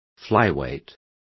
Complete with pronunciation of the translation of flyweights.